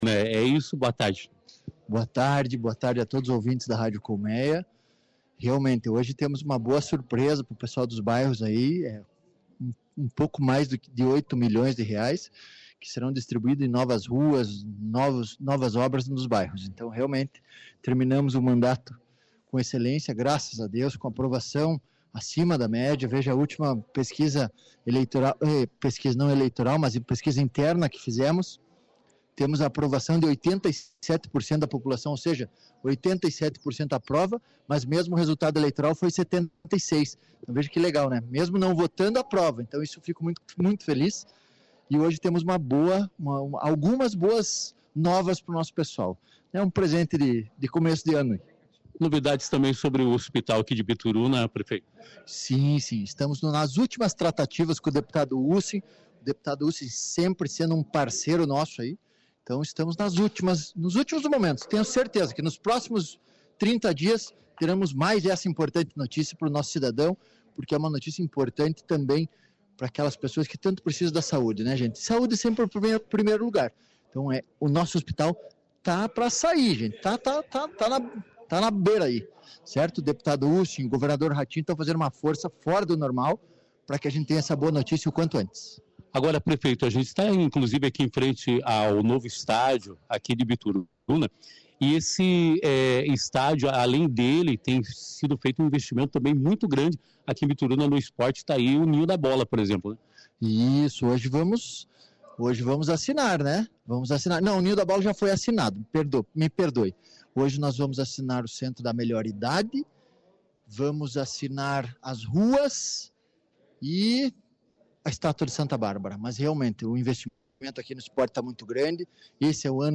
Na oportunidade o presidente da Câmara de Vereadores, Tiago Ribas, também falou sobre o trabalho realizado na Casa de Leis em conjunto com o poder Executivo.